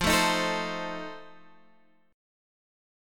Listen to F7 strummed